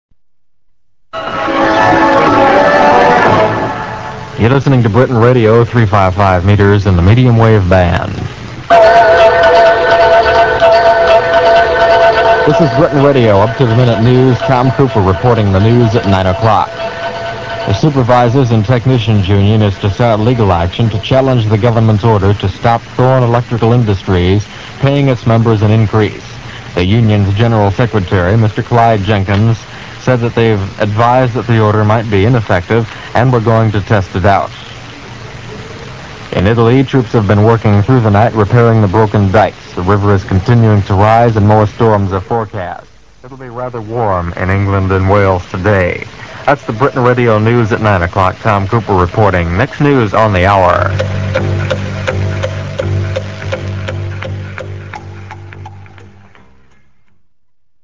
reading the news on Britain Radio